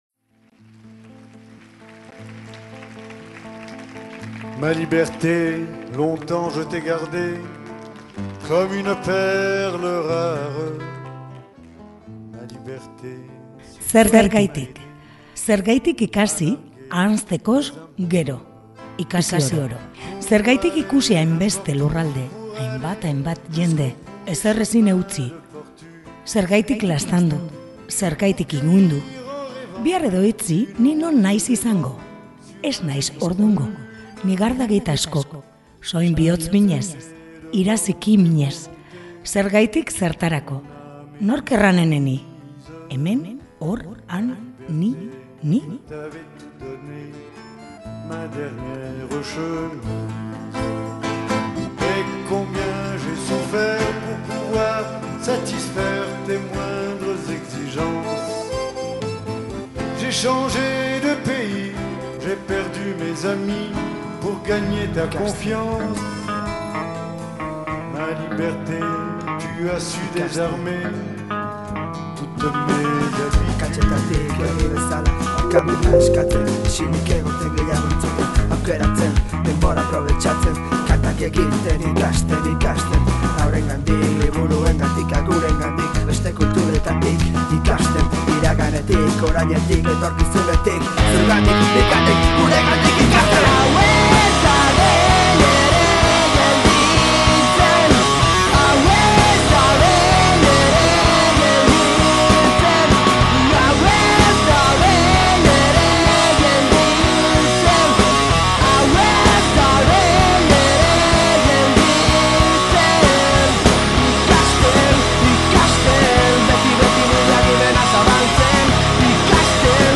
PIPERPOLIS: Guda Dantzan taldearekin solasean, “Azkena eta Bogoaz” agur kontzertuaren aurretik - Arrosa
Atsedena hartzera doazen lagunak izango ditugu gaur Piperpolisen: Guda Dantza musika taldea. 2012an sortu zen Uribe Kostan (Bizkaia), eta estilo aniztasuna izan du beti bere ezaugarri nagusietako bat.